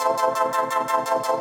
Index of /musicradar/shimmer-and-sparkle-samples/170bpm
SaS_MovingPad02_170-C.wav